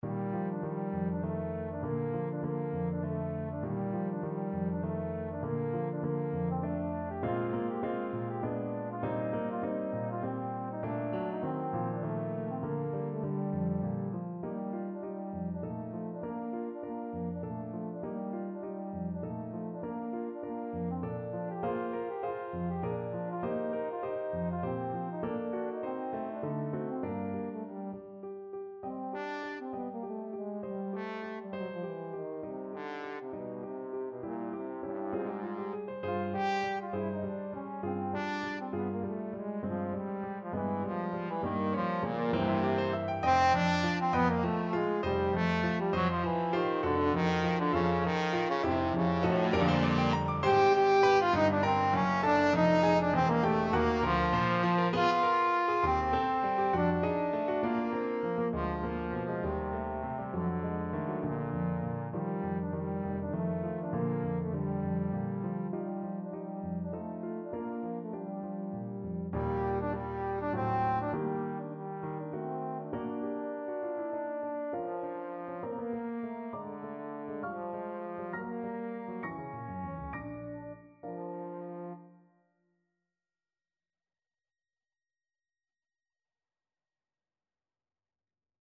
3/4 (View more 3/4 Music)
Allegro giusto =200 (View more music marked Allegro)
Classical (View more Classical Trombone Music)